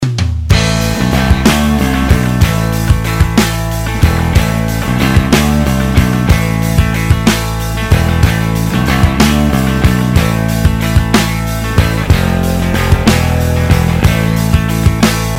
• Качество: 192, Stereo
Этот потрясающий проигрыш